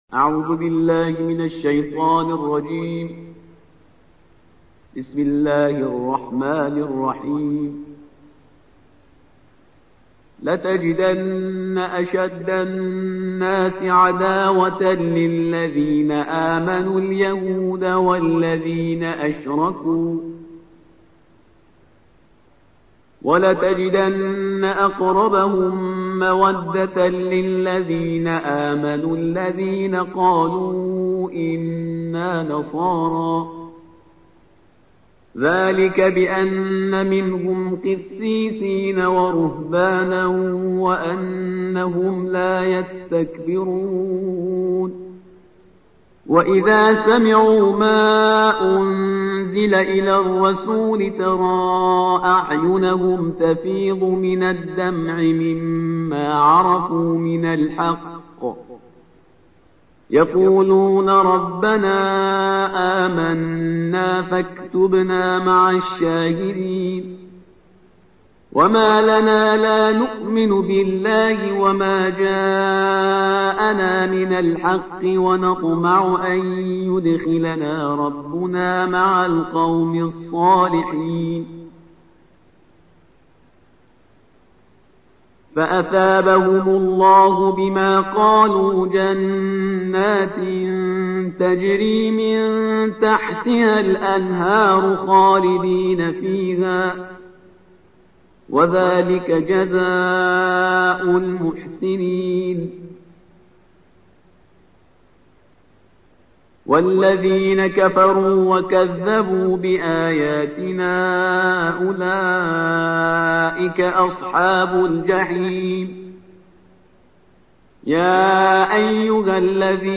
تلاوت ترتیل جزء هفتم کلام وحی با صدای استاد